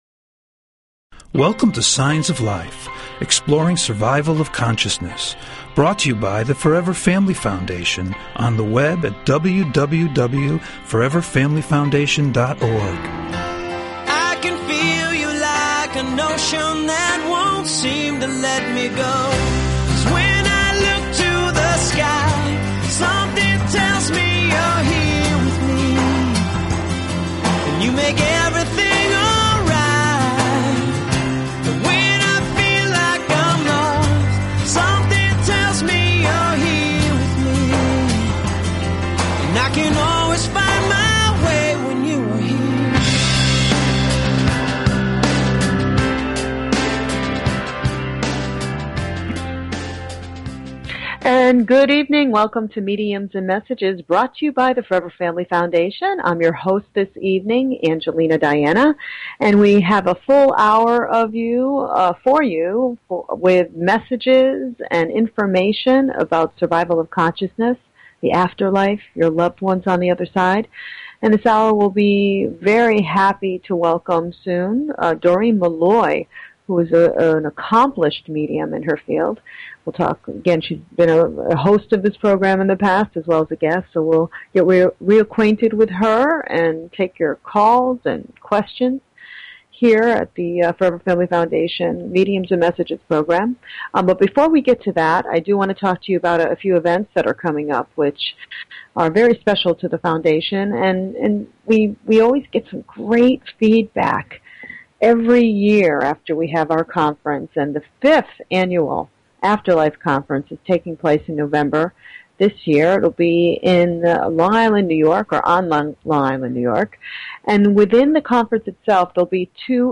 Talk Show Episode, Audio Podcast, Signs_of_Life and Courtesy of BBS Radio on , show guests , about , categorized as
Call In or just listen to top Scientists, Mediums, and Researchers discuss their personal work in the field and answer your most perplexing questions.